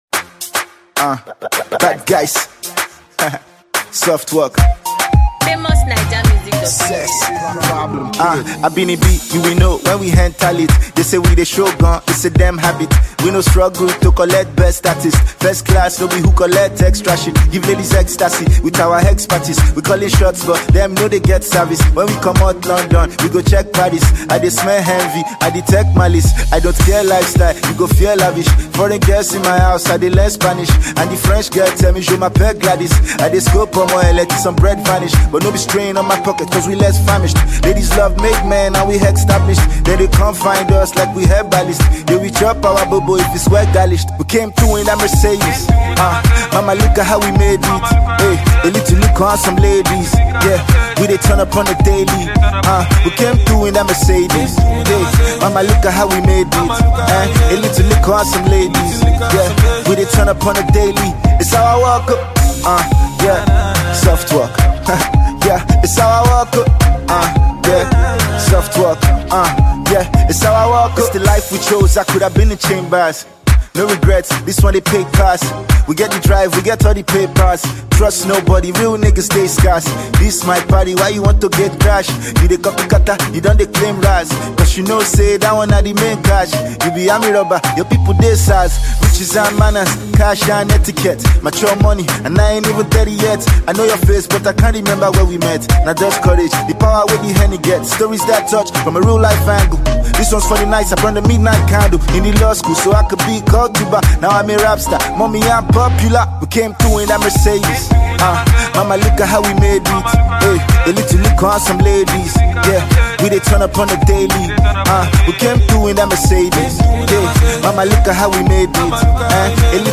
hip-jump